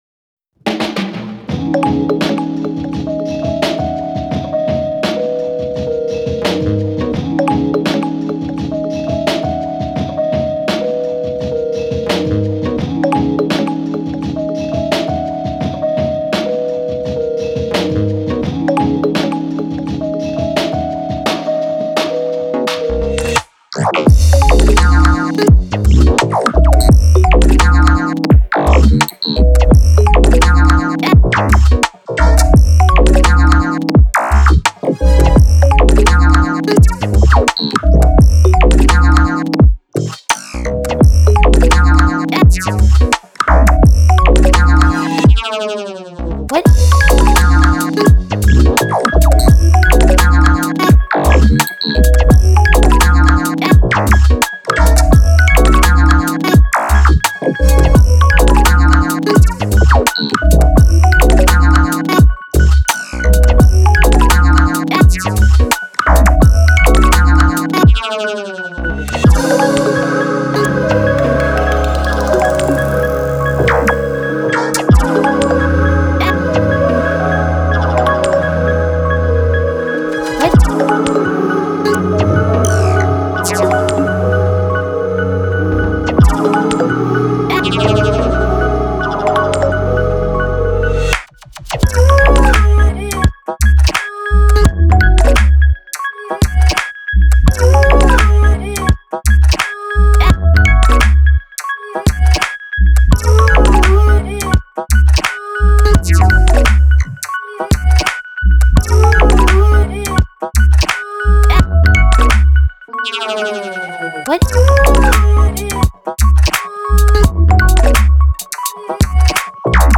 Accompanied by my music and my voice.